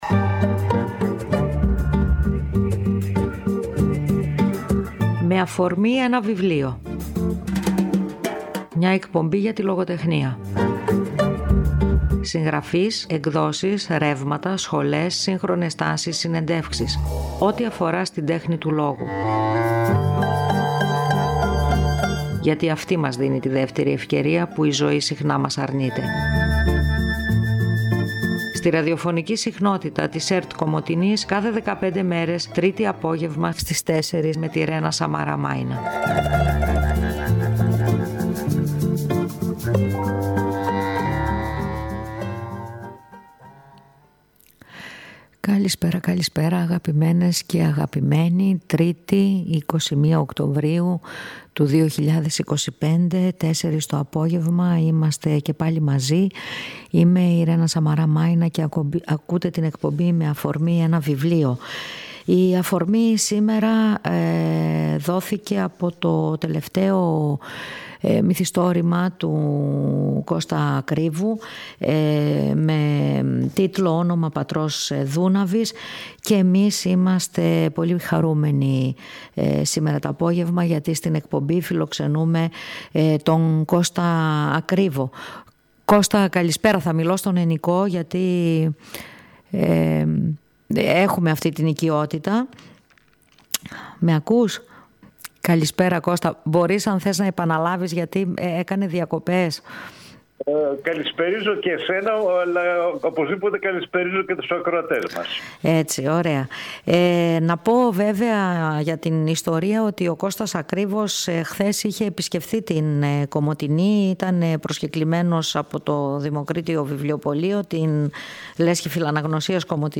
Μια εκπομπή για το βιβλίο και τη λογοτεχνία.